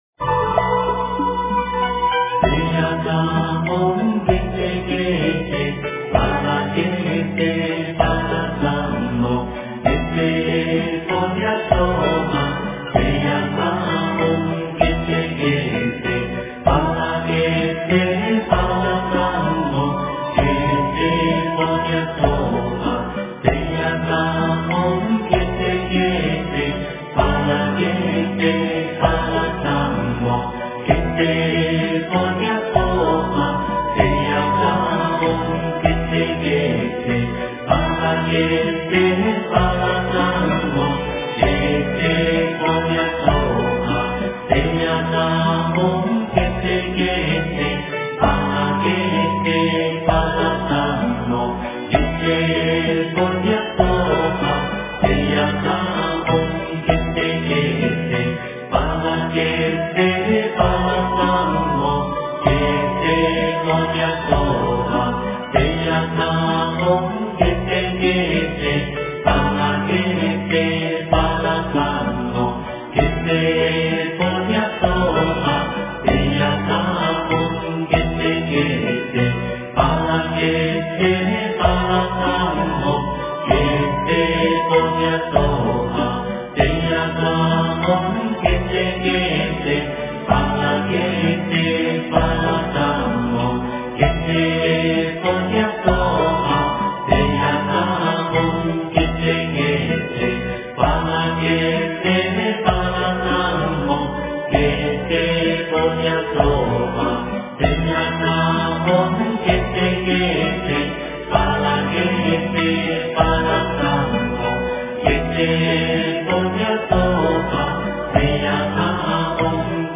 般若心咒--男女合唱版
般若心咒--男女合唱版 真言 般若心咒--男女合唱版 点我： 标签: 佛音 真言 佛教音乐 返回列表 上一篇： 大吉祥天女咒--佚名 下一篇： 七佛灭罪真言--佚名 相关文章 般若佛母心咒--佛音佛语 般若佛母心咒--佛音佛语...